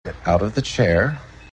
Here’s one of my favorite Zach ringtones – Spock’s “Get out of the chair”!